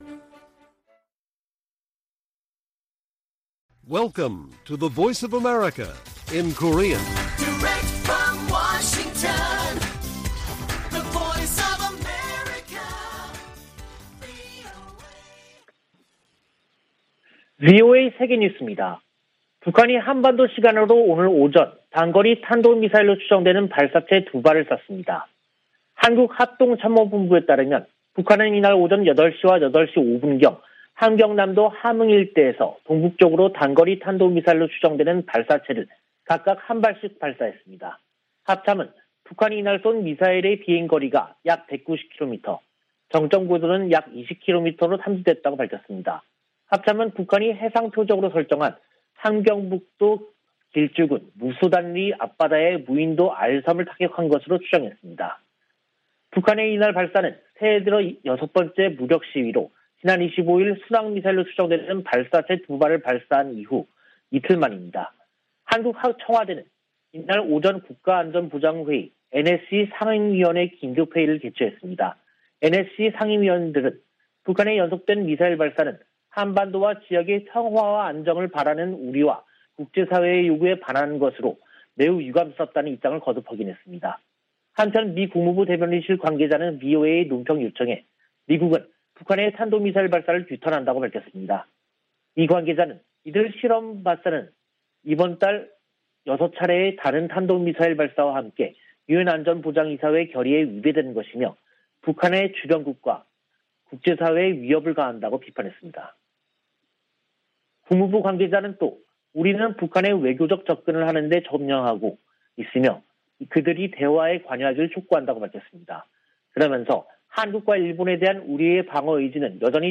VOA 한국어 간판 뉴스 프로그램 '뉴스 투데이', 2022년 1월 27일 2부 방송입니다. 북한이 또 단거리 탄도미사일로 추정되는 발사체 2발을 동해상으로 쐈습니다.